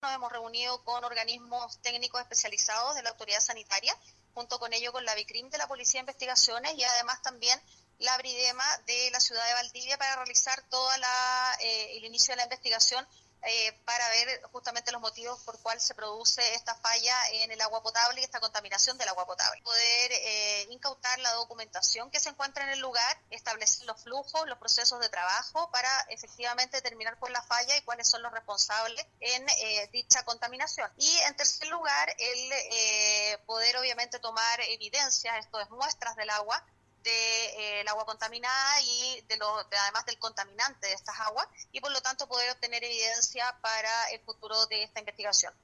La fiscal jefe de Osorno, María Angélica de Miguel, indicó que se reunió con técnicos especializados de la autoridad sanitaria, la brigada investigadoras de delitos contra el medio ambiente de Valdivia y la Brigada de Investigación Criminal.